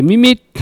Cri pour appeler le chat ( prononcer le cri )